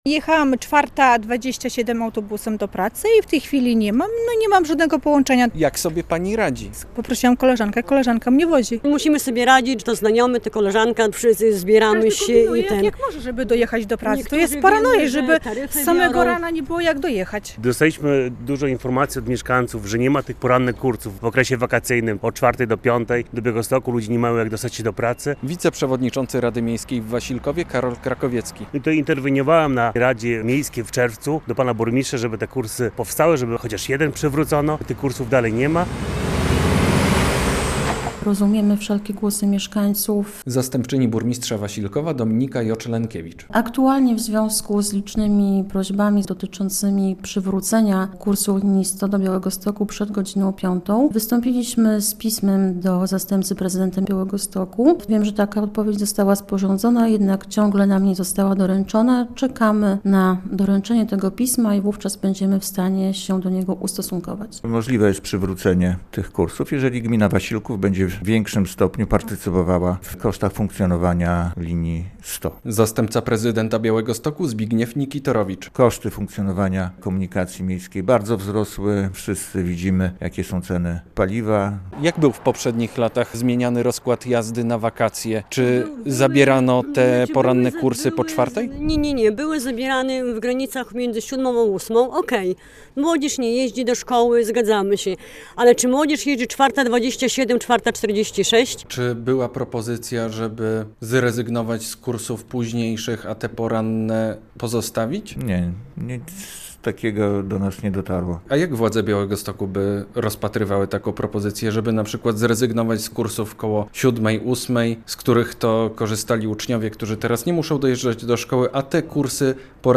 "Jechałam 4:27 autobusem do pracy i w tej chwili nie mam żadnego połączenia (...) Musimy sobie radzić. Znajomi nas podwożą, niektórzy zamawiają taksówki" - mówią nam mieszkanki Wasilkowa pracujące w Białymstoku.
Zastępca prezydenta Białegostoku Zbigniew Nikitorowicz mówi, że przywrócenie porannych kursów autobusów jest możliwe pod warunkiem, że gmina Wasilków będzie dokładać więcej pieniędzy do utrzymania linii 100.